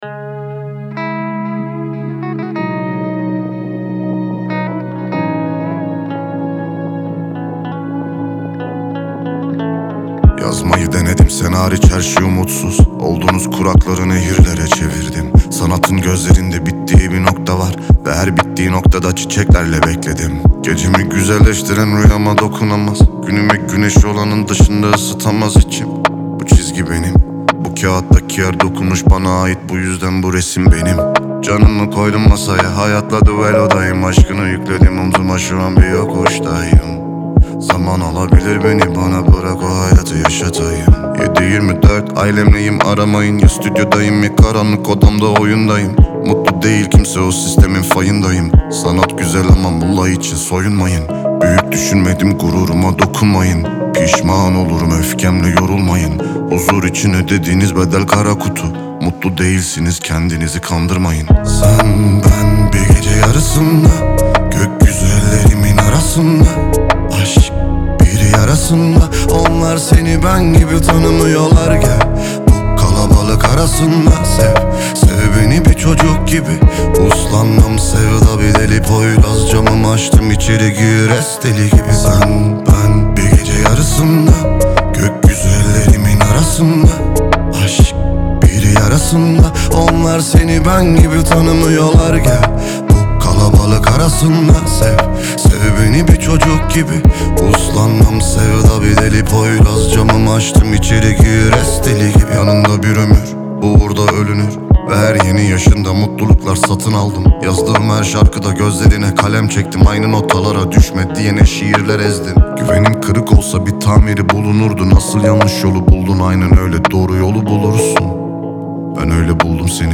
Трек размещён в разделе Рэп и хип-хоп / Турецкая музыка.